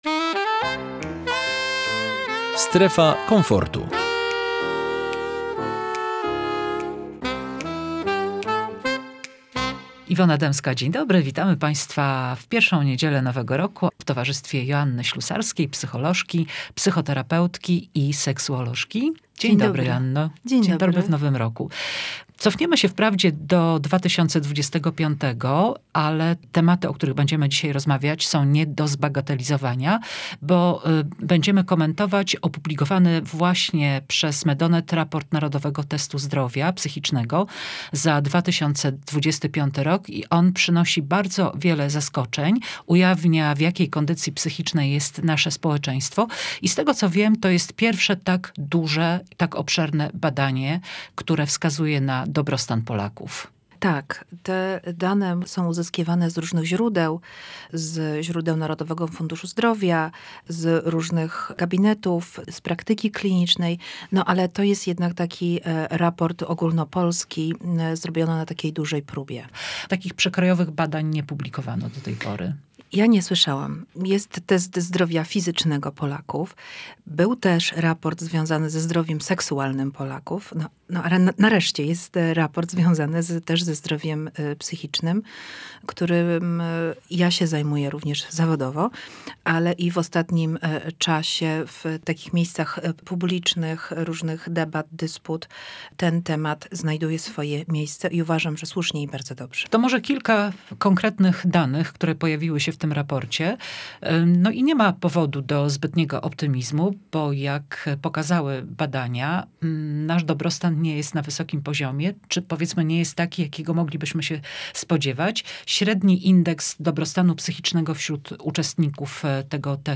Posłuchaj rozmowy z psycholożką, psychoterapeutką i seksuolożką